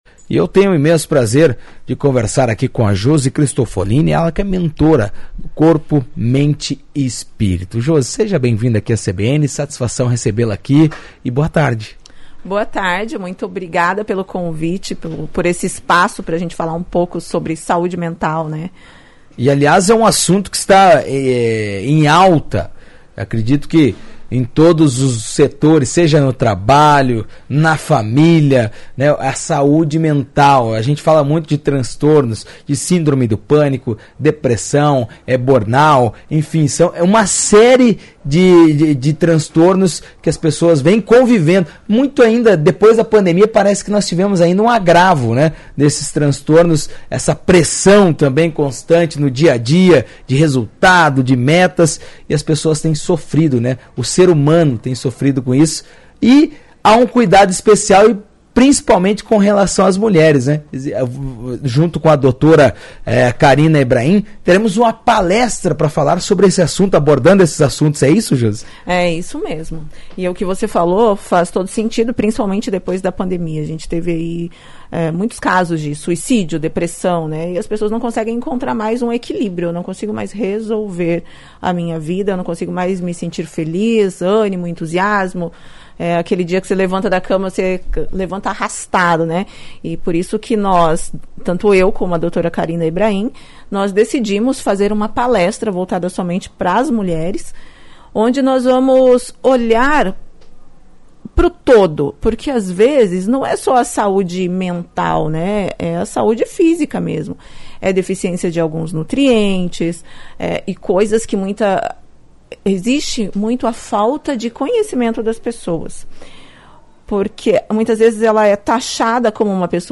comentou em entrevista à CBN sobre os obstáculos do mundo atual em relação aos cuidados com o corpo, saúde mental e também espiritual.